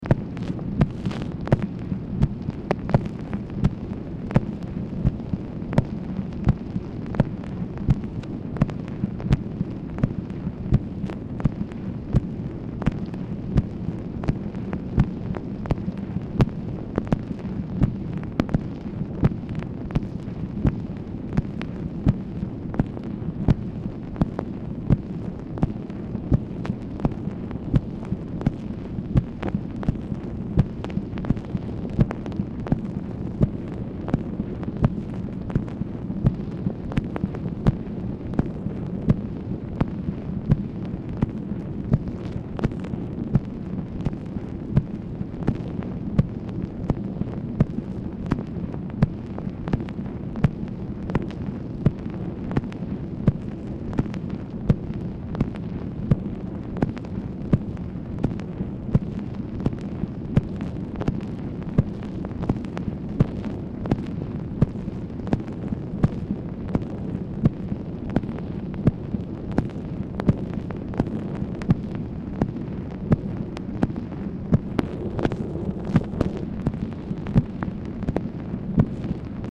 Telephone conversation # 8366, sound recording, MACHINE NOISE, 7/21/1965, time unknown | Discover LBJ
Telephone conversation
Dictation belt